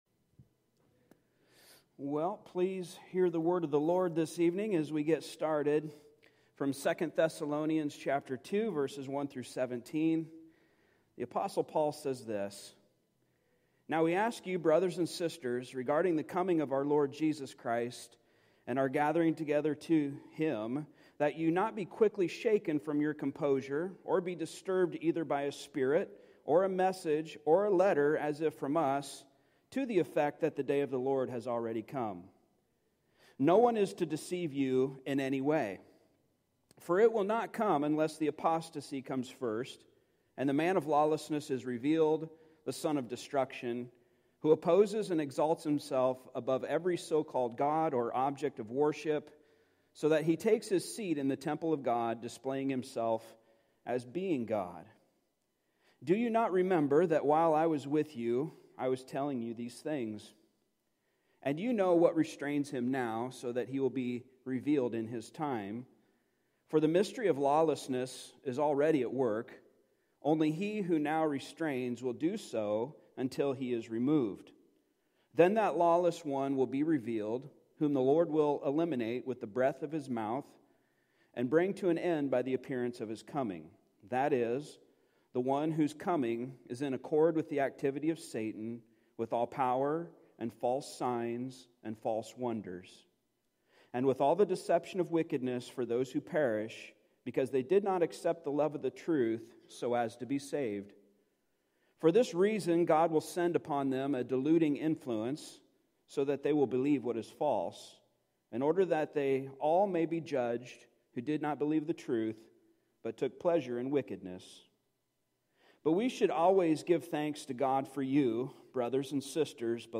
Service Type: Midweek Service Topics: Early Church , Heresy , Irenaeus